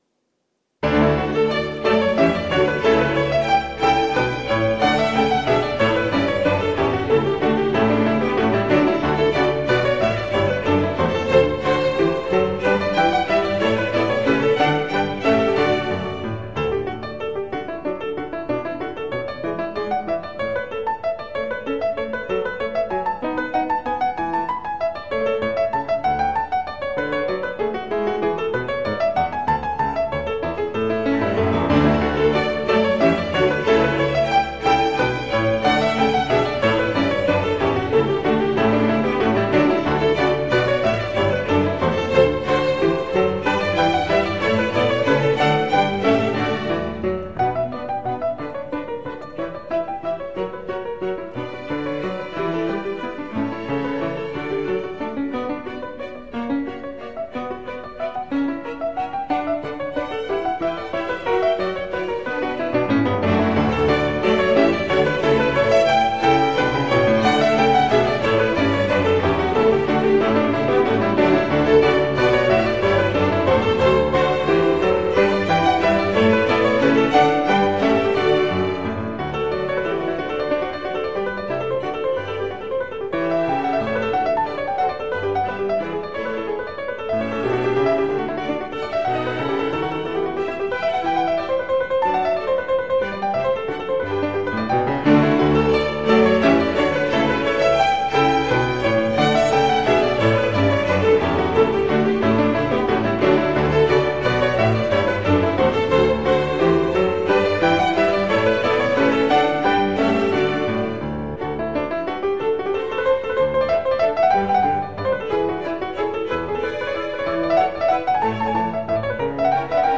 清晰、 有力、线条分明，充满了旋律的灵动
专辑曲目： Concerto for Piano and Orchestra No. 3 in D Major